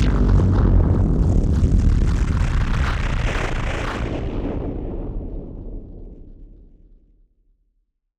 BF_SynthBomb_C-02.wav